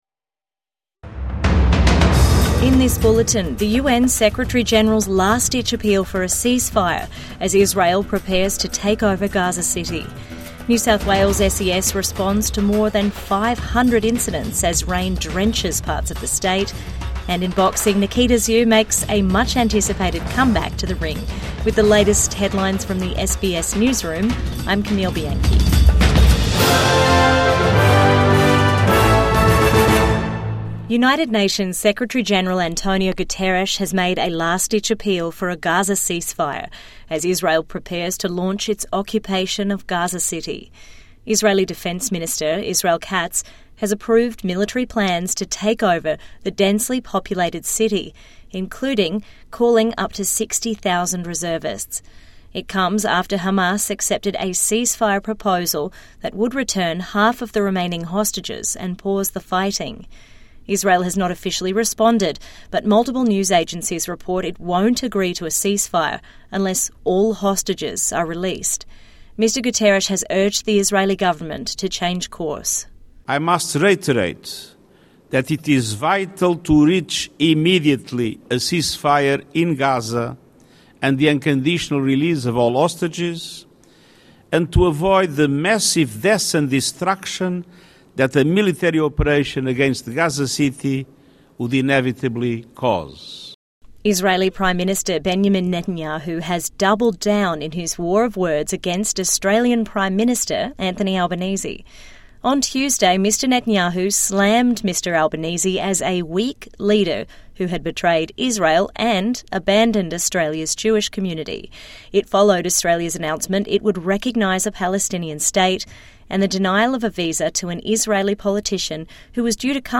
UN leader makes appeal to Israel over Gaza City | Evening News Bulletin 21 August 2025